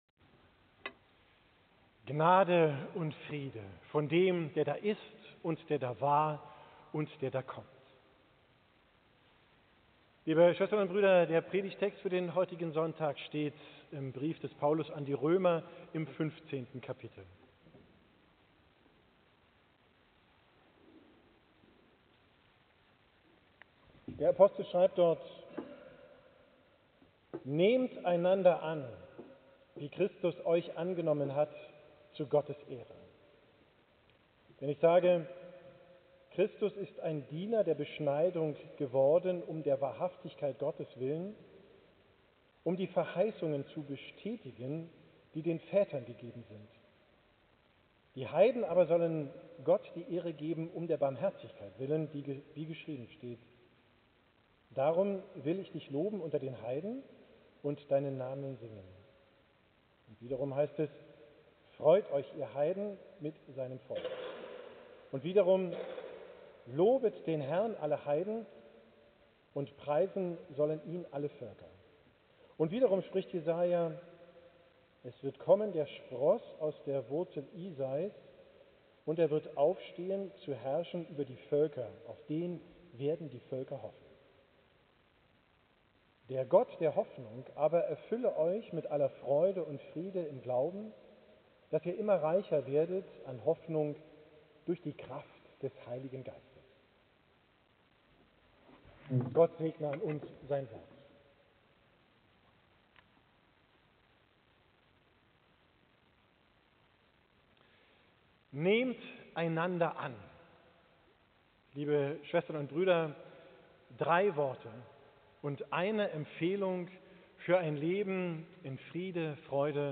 Predigt vom 3. Sonntag im Advent, 15. XII 24, von Pastor